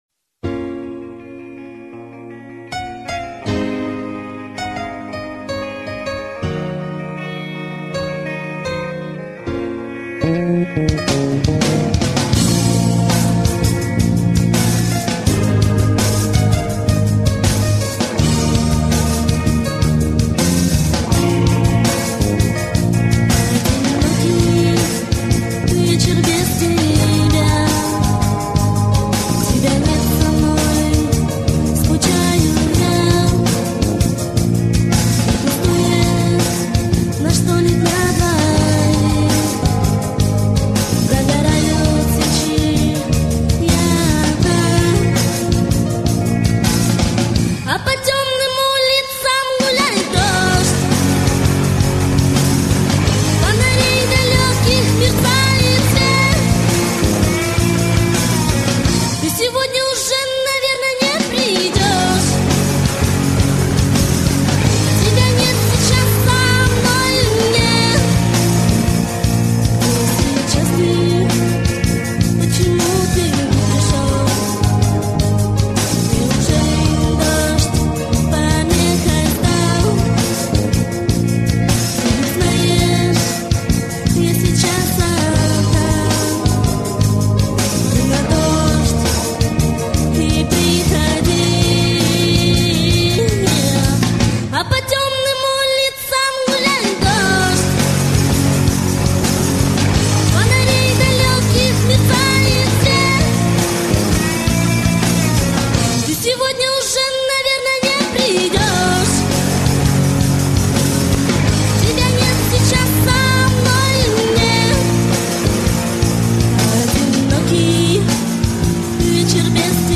zenskij_rep_gulaet_.mp3